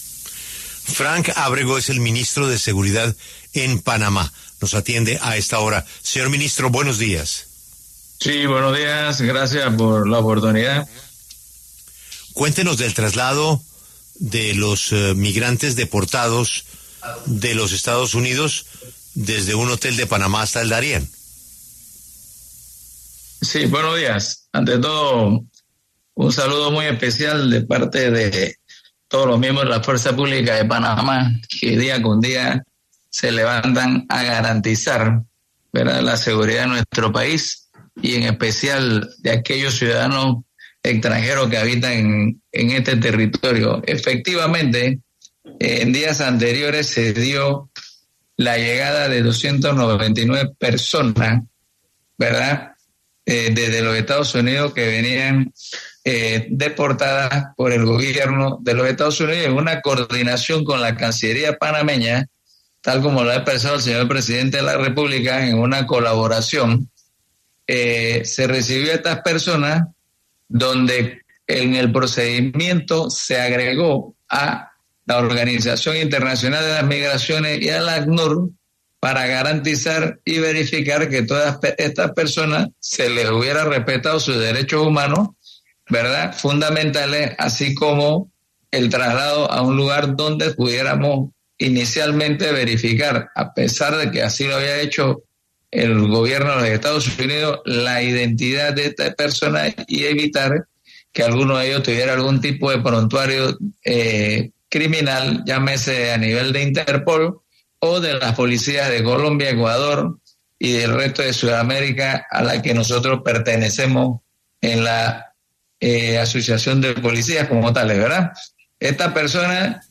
El ministro de Seguridad Pública de Panamá, Frank Ábrego, conversó con La W sobre la situación en la que se encuentran más de 100 migrantes deportados de los Estados Unidos.